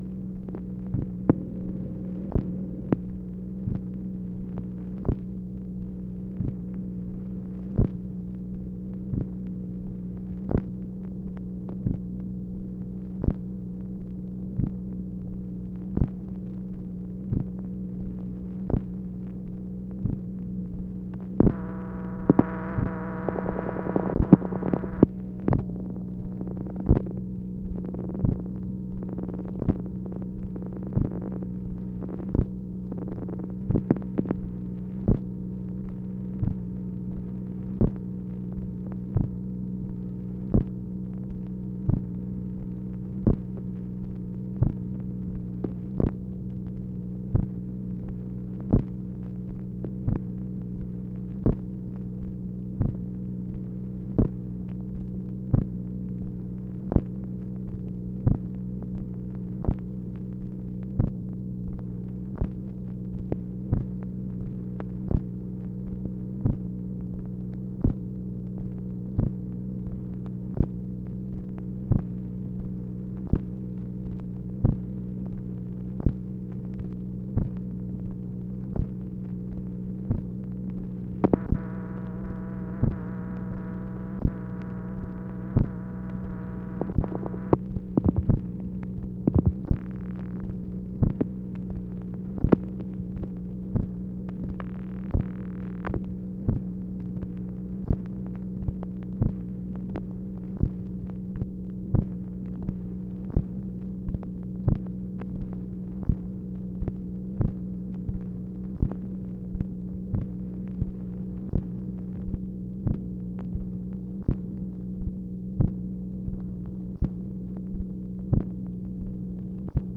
MACHINE NOISE, January 22, 1964